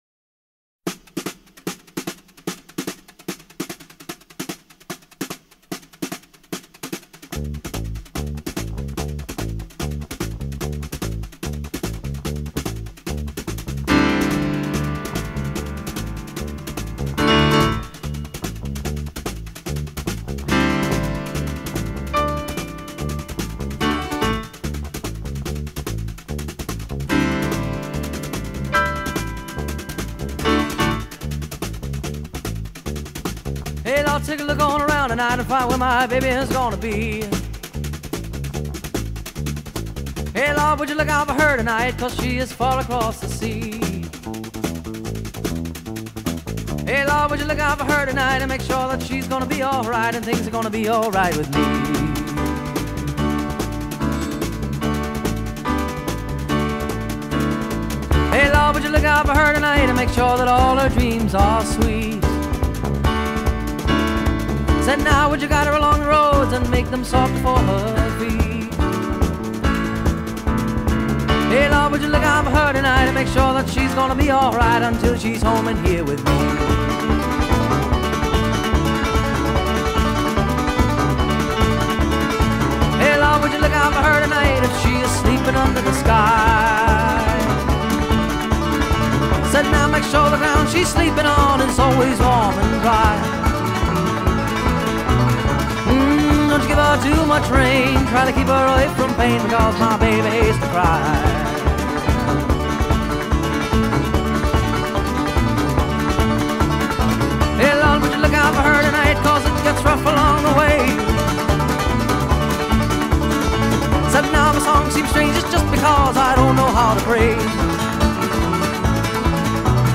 Pop Rock, Piano Rock